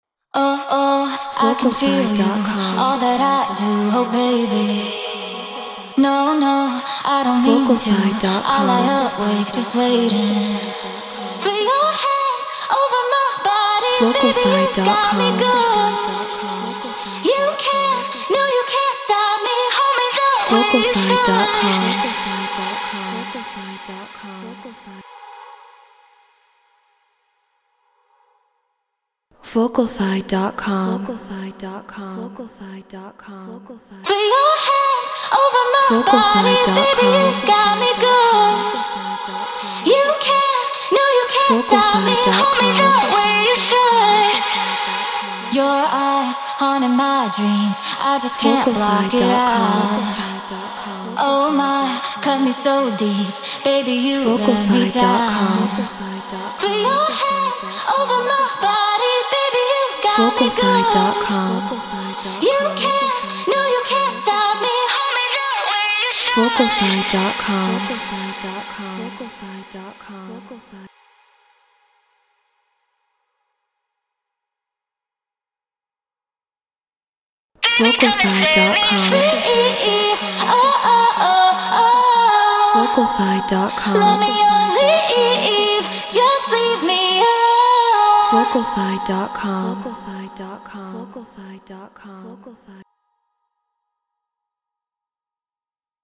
Hard Dance 170 BPM G#min
Flea 47 Apogee Symphony Mark ii Logic Pro Treated Room